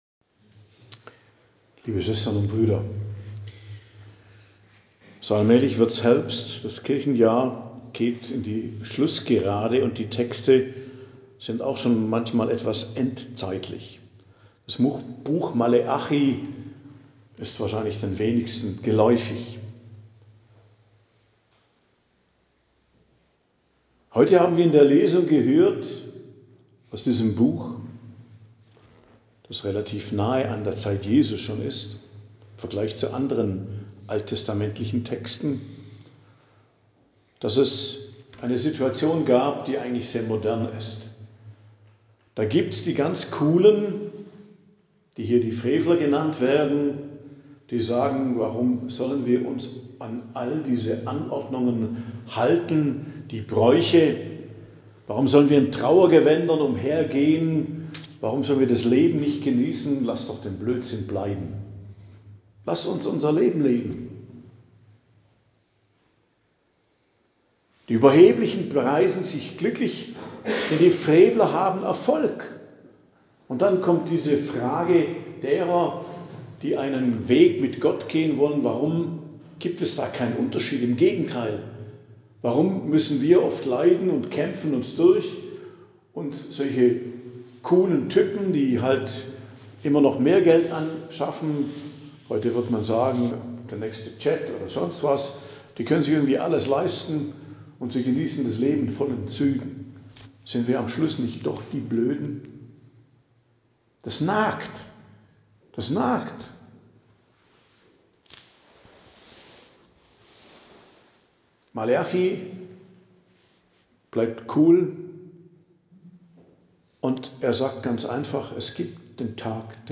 Predigt am Donnerstag der 27. Woche i.J., 9.10.2025